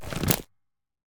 creaking_unfreeze2.ogg